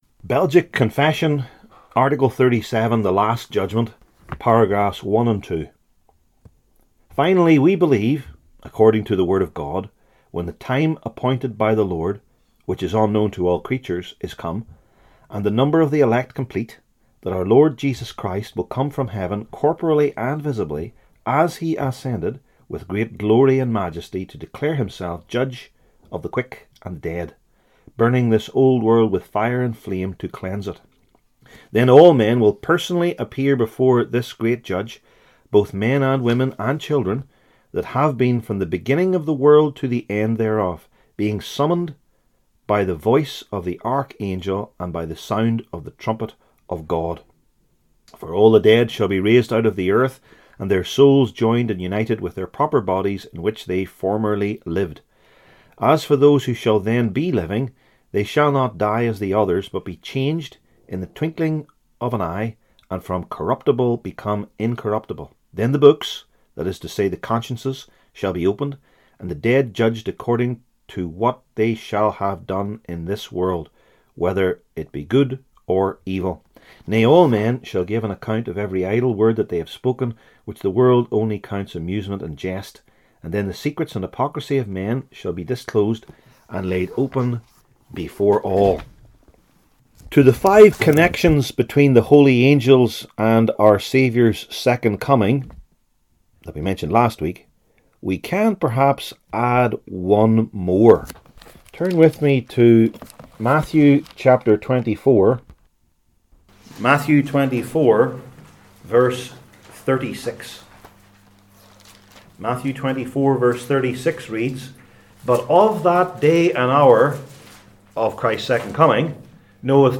Passage: I Thessalonians 4:13-18 Service Type: Belgic Confession Classes